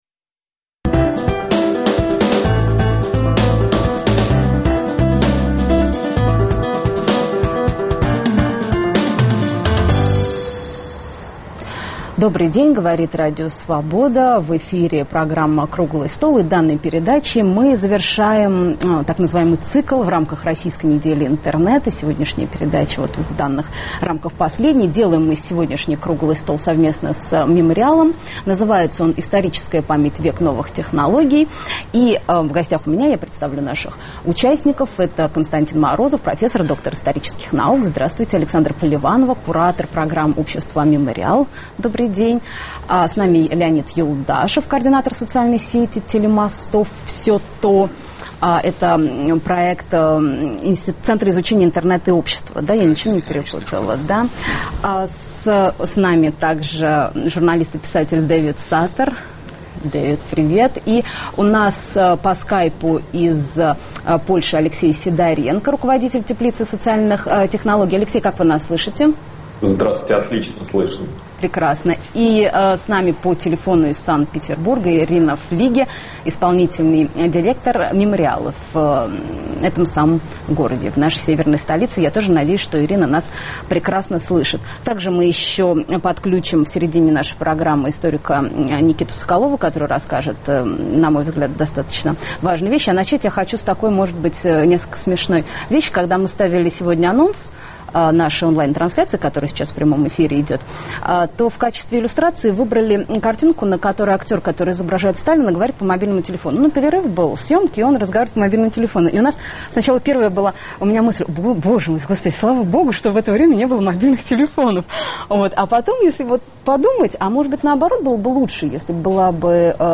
Онлайн-трансляция Круглого стола в рамках Российской недели интернета.